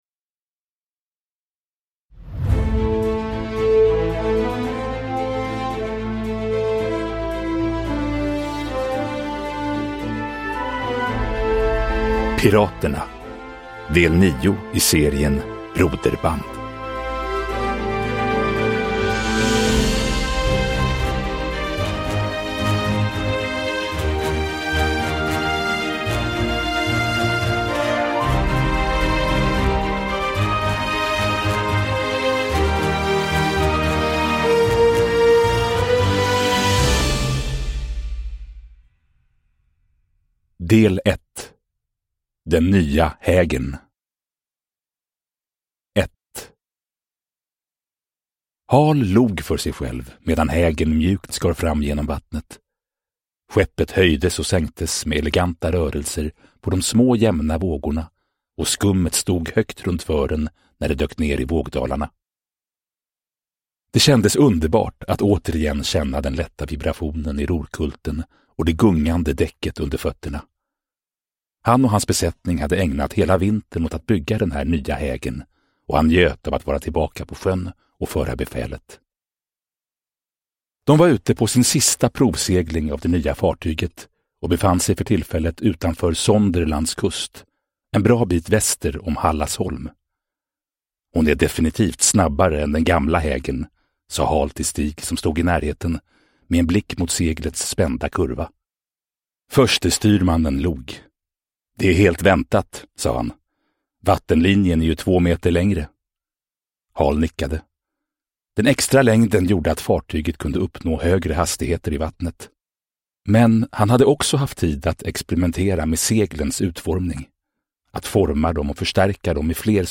Piraterna – Ljudbok